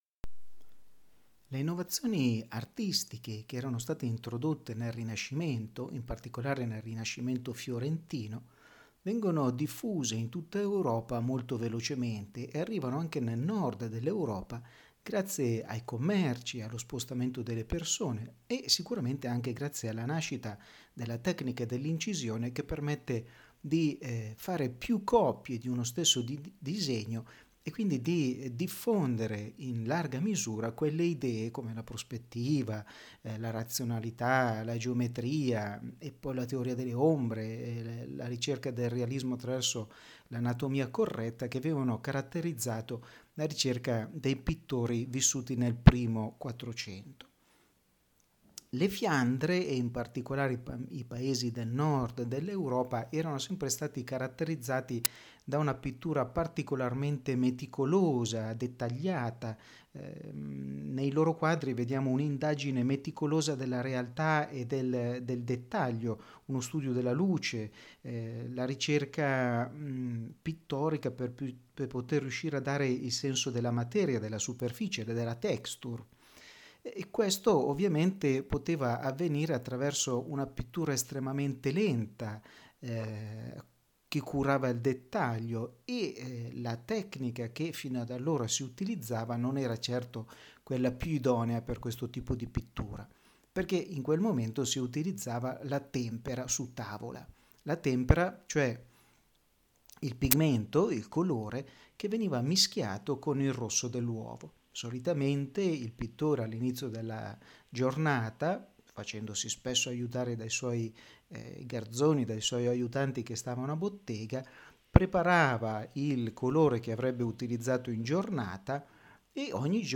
Ascolta la lezione audio dedicata a Van Eyck